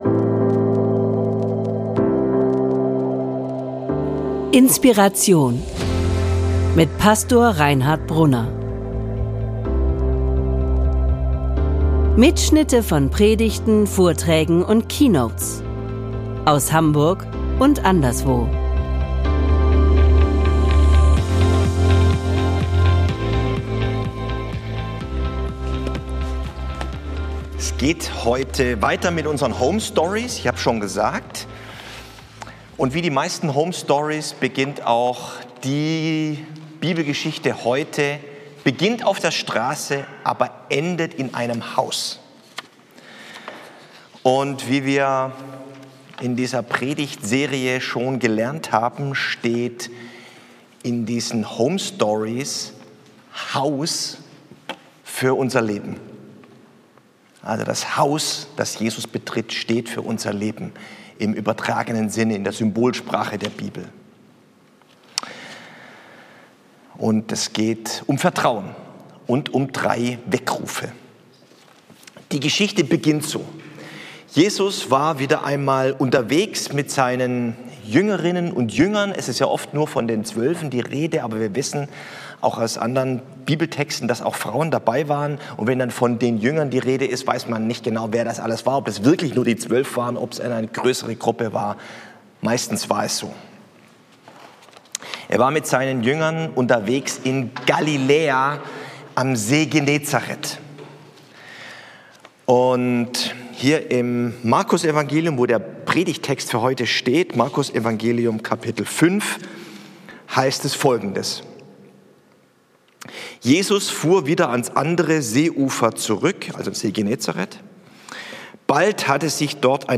Wenn deine Welt zusammenbricht - Worte des Trostes aus Markus 5 ~ INSPIRATION - Predigten und Keynotes Podcast